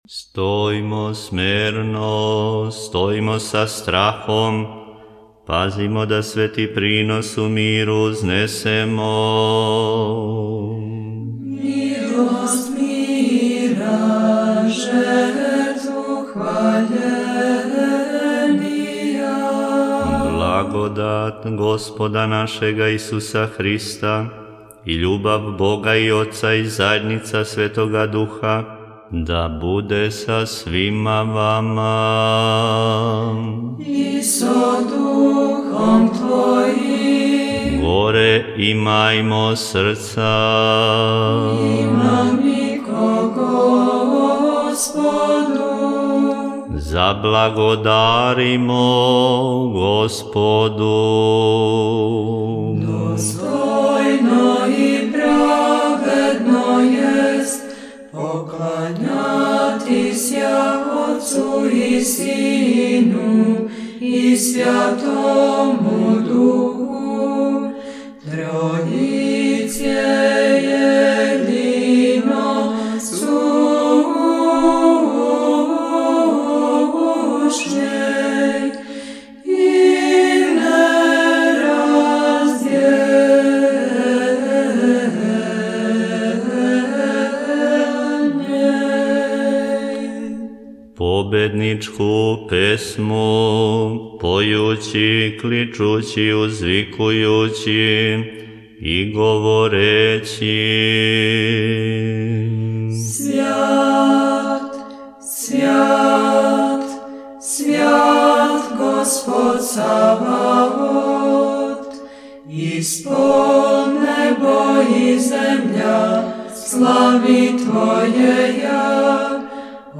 Са Свете Литургије